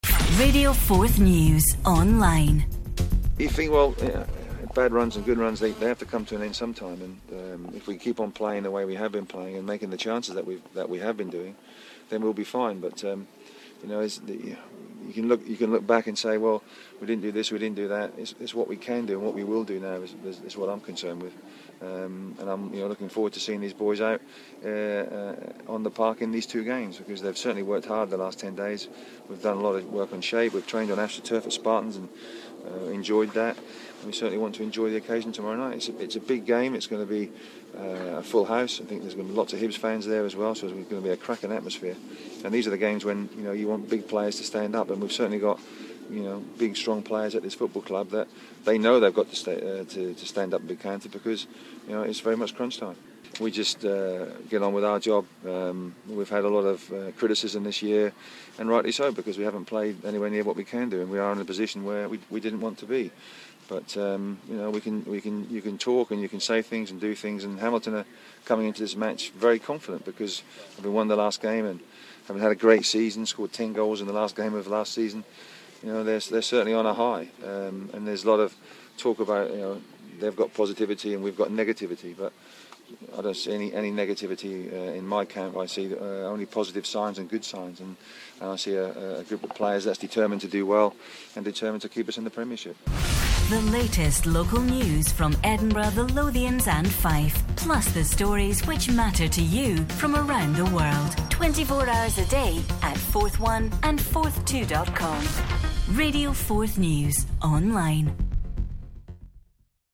Terry Butcher speaks to Forth news ahead of the crucial play-off clash at Hamilton.